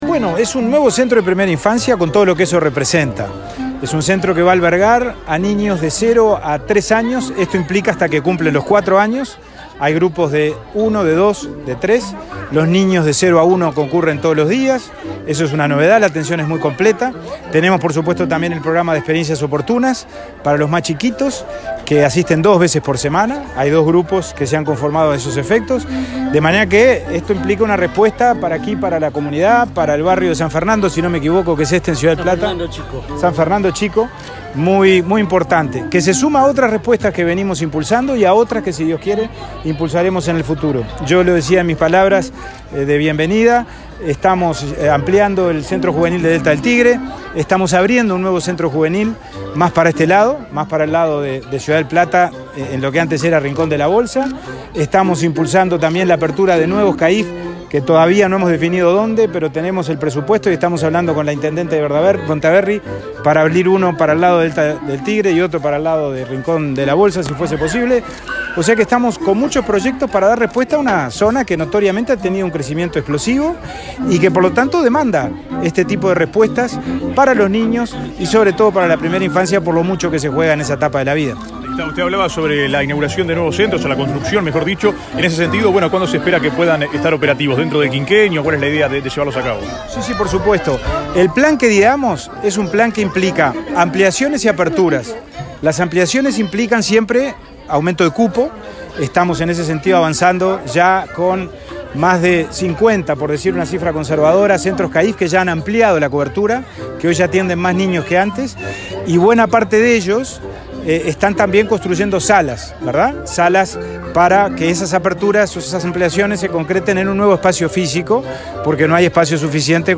Declaraciones del presidente de INAU, Pablo Abdala
El presidente de INAU, Pablo Abdala, inauguró un centro CAIF en Ciudad del Plata, departamento de San José. Luego, dialogó con la prensa.